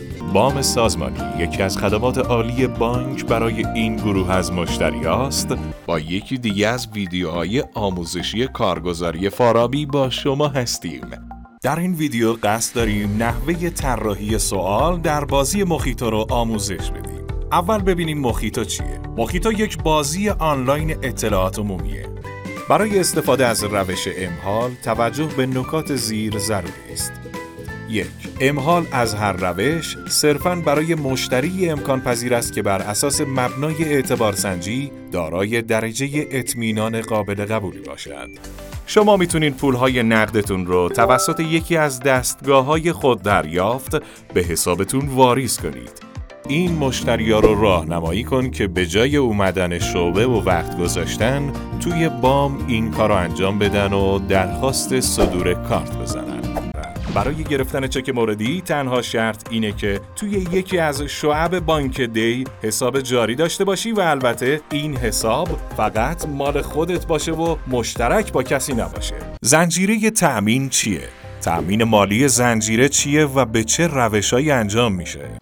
آموزش الکترونیک
🎵 محاوره و ملایم